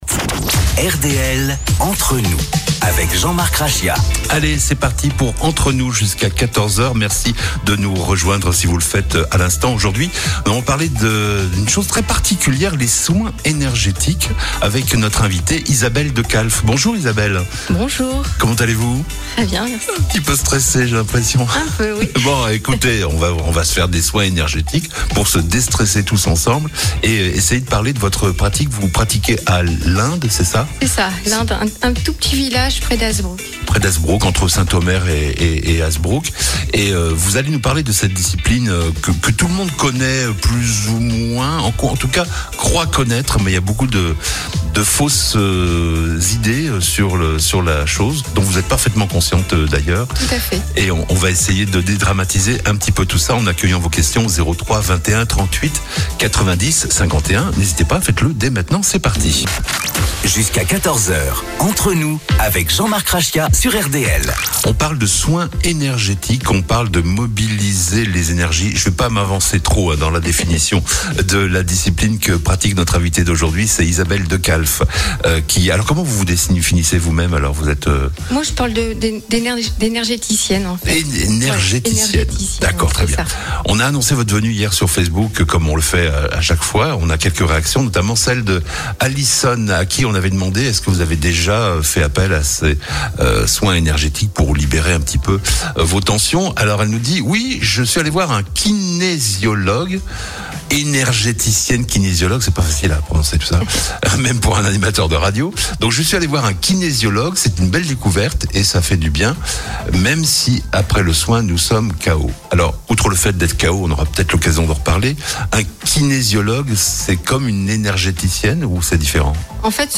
Pour celles et ceux qui préfèrent l’écoute à la lecture, j’ai eu l’occasion de participer à une émission de radio autour des soins énergétiques, où j’explique cette approche et ce qu’elle peut apporter. Extrait d’une émission de radio consacrée aux soins énergétiques, diffusée sur Radio RDL , dans l’émission Entre Nous